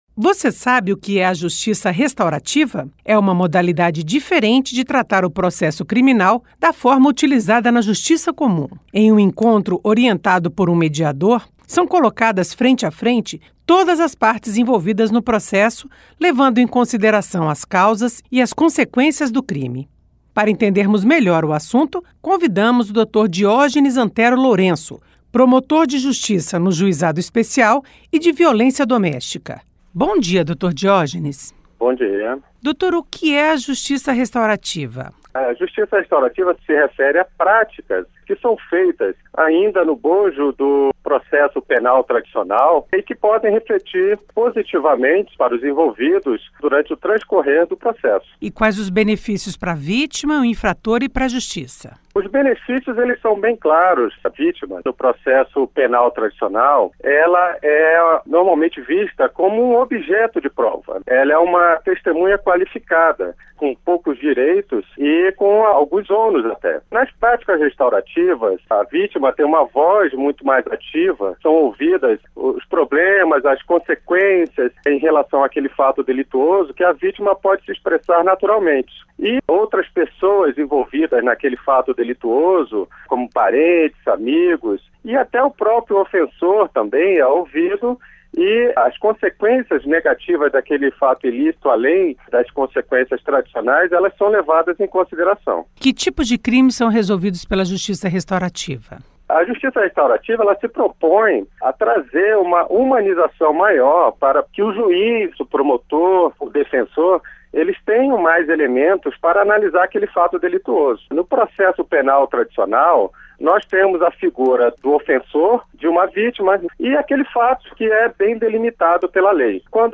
Entrevista com promotor de Justiça Diógenes Antero Lourenço.